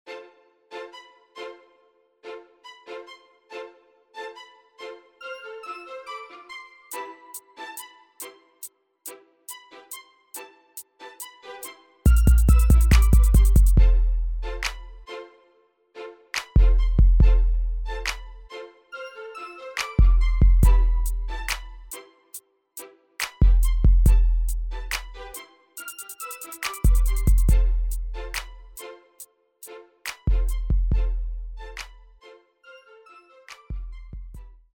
R&B / Pop
R&B Beats Pop Instrumentals